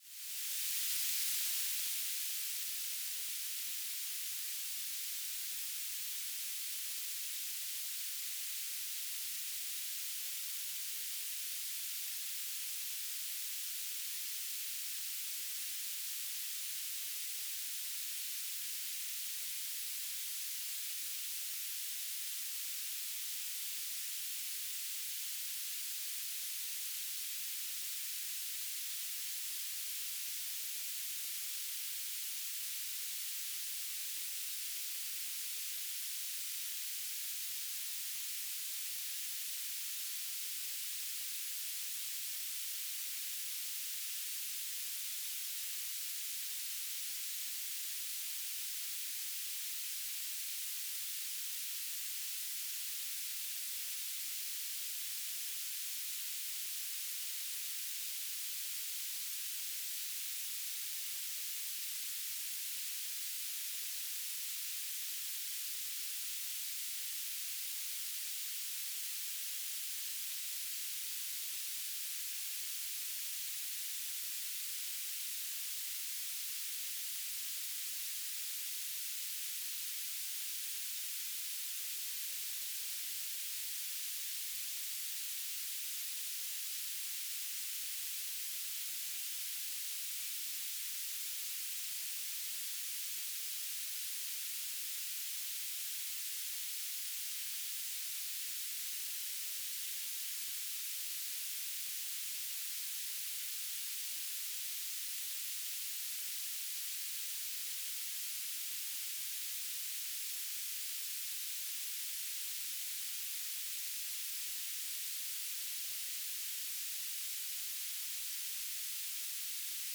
"transmitter_description": "Mode U - BPSK9k6 G3RUH - Beacon",